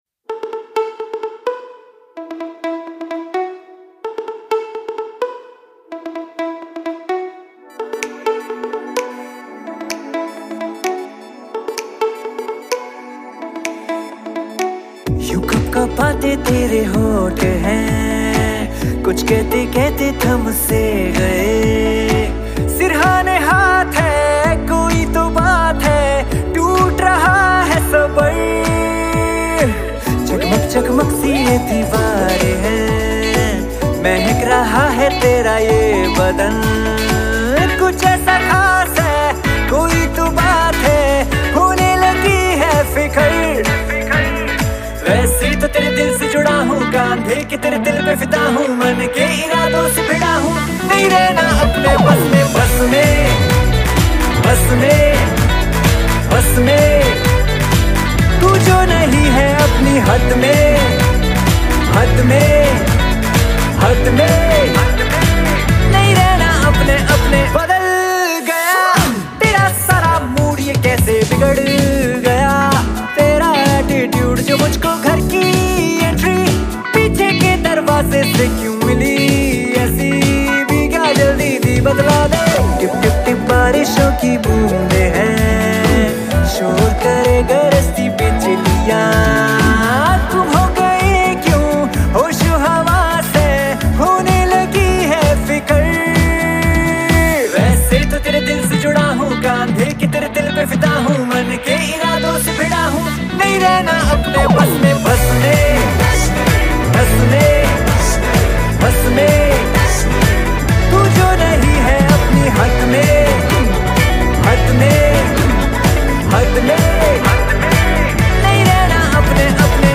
Indian POP